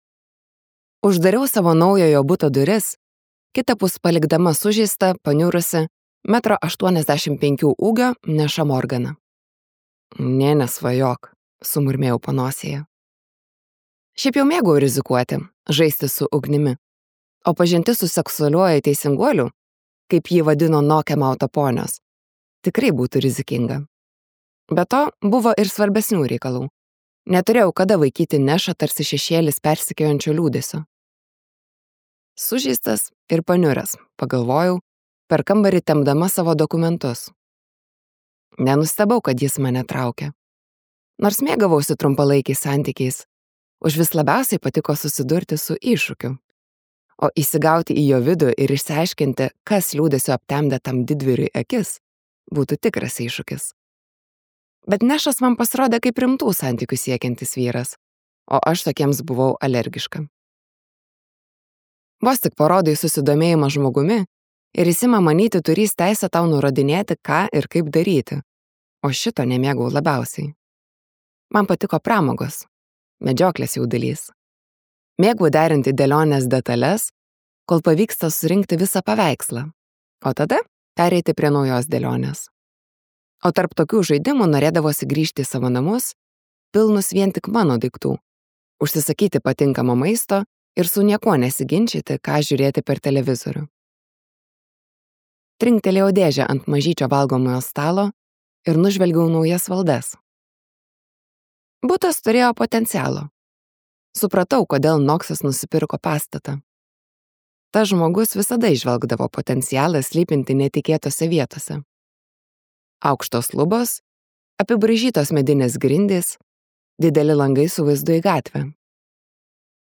Lucy Score audioknyga „Tai, kas slypi tamsoje“ – tai jaudinanti mažo miestelio romantinė istorija apie paslaptis, antrąsias galimybes ir emocinį išgijimą.